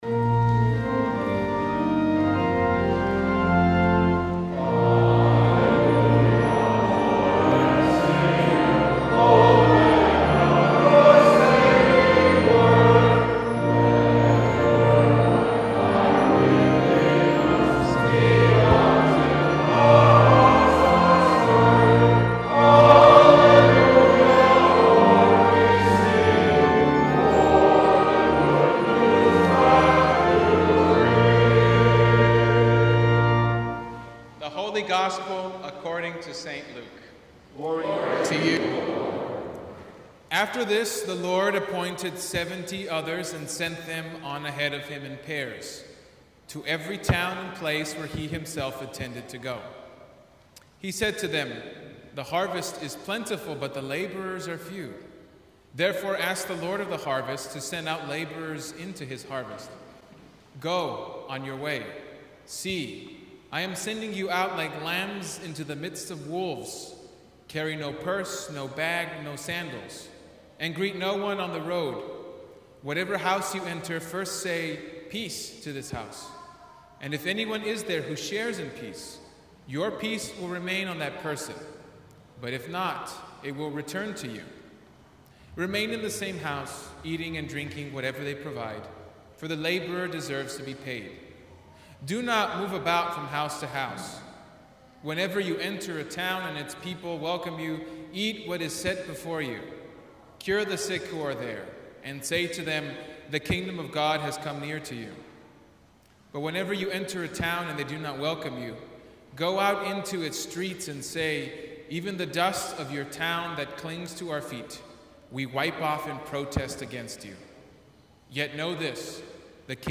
Sermons from Christ the King, Rice Village | Christ The King Lutheran Church